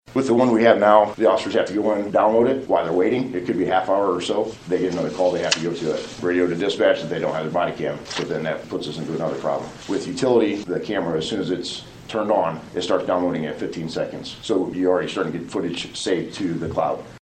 LaGrange County Sheriff Tracy Harker made a request to the LaGrange County Council Monday about some new body and vehicle cameras he would like to get for his department.